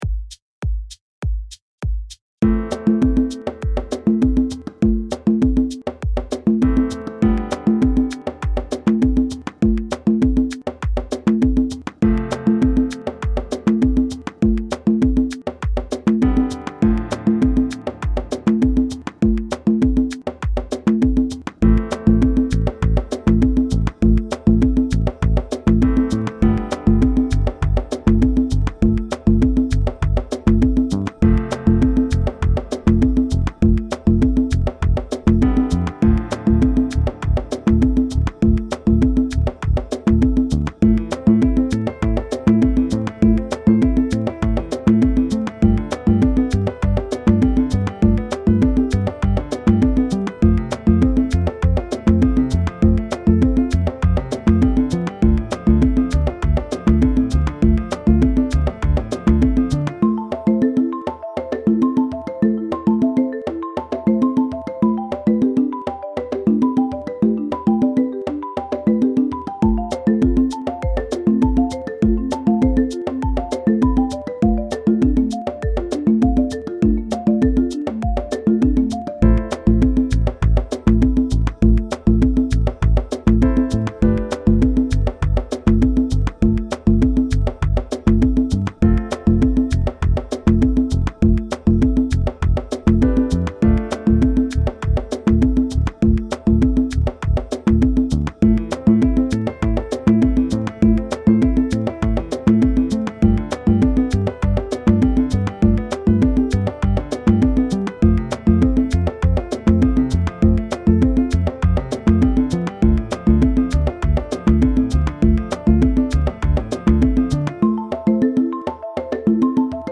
Play along with this Afro-House inspired mix track
audio (reverie mix)